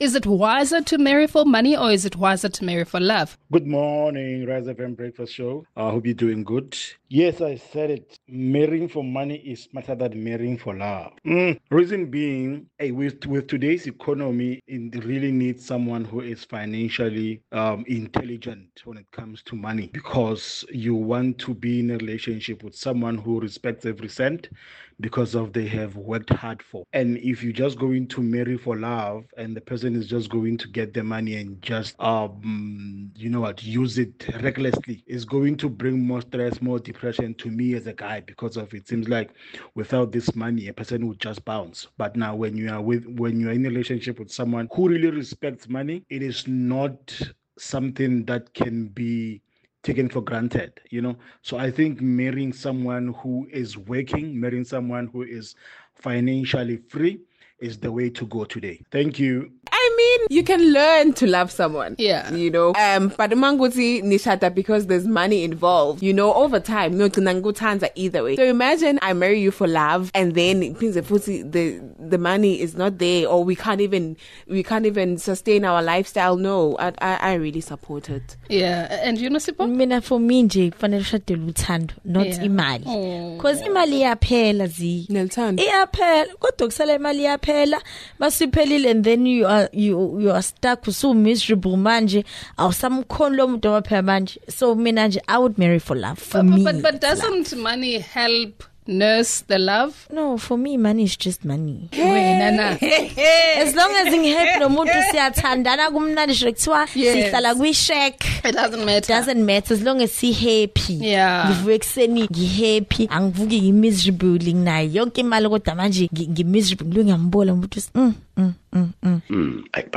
In one of the recent segments, a listener shared a voice note arguing…